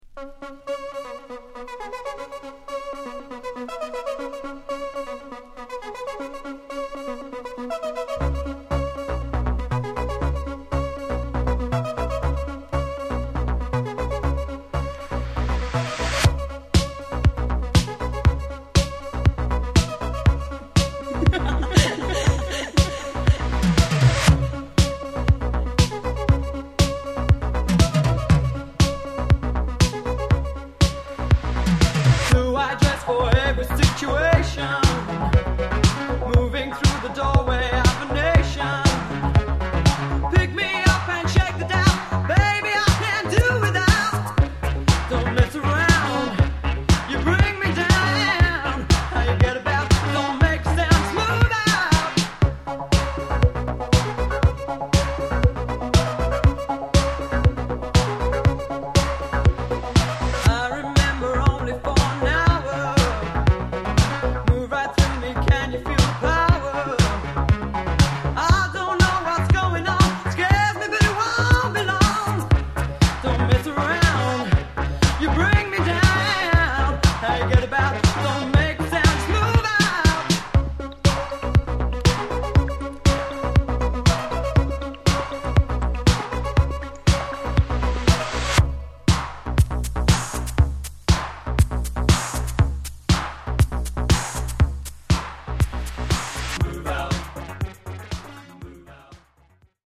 Genre: Techno/Synth Pop